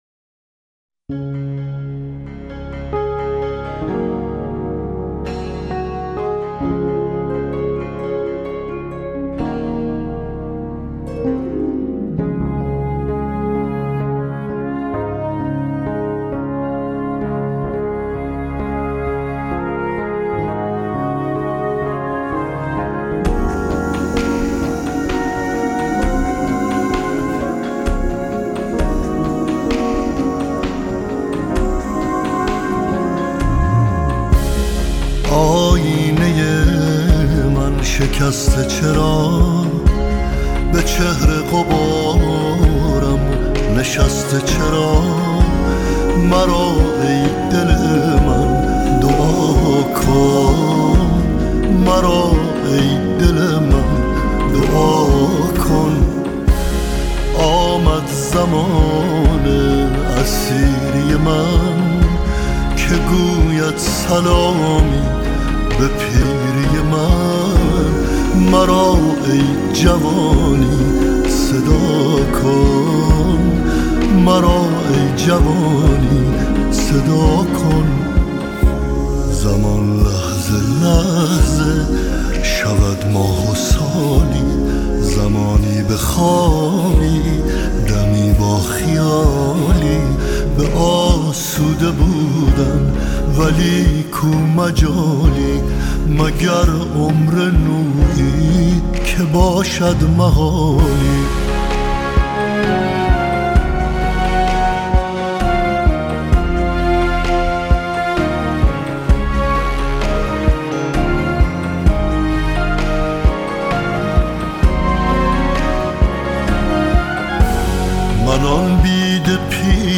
آهنگ قدیمی
امان از اشک آهنگ غمگین